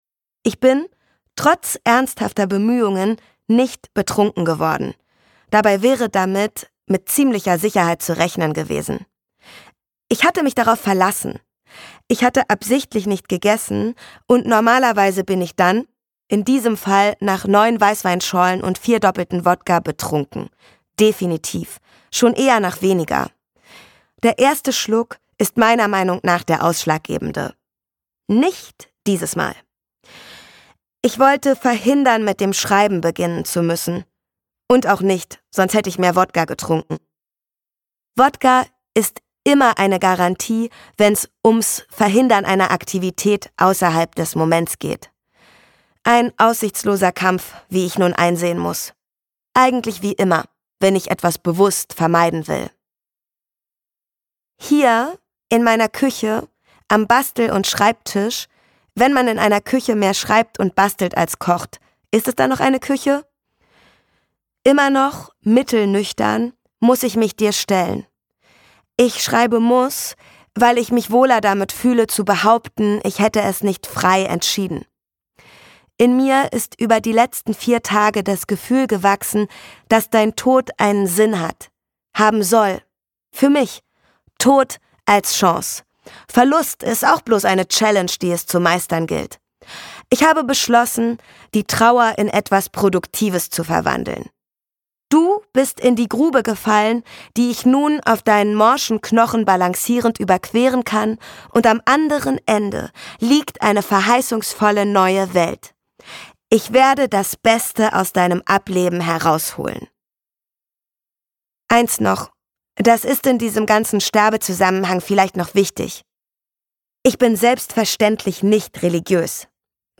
Gekürzt Autorisierte, d.h. von Autor:innen und / oder Verlagen freigegebene, bearbeitete Fassung.
Hörbuchcover von Es war nicht anders möglich